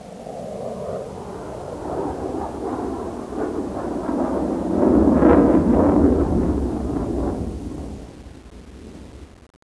aircraft_distant_flyby3.wav